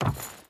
SFX / Footsteps / Wood
Wood Chain Run 2.wav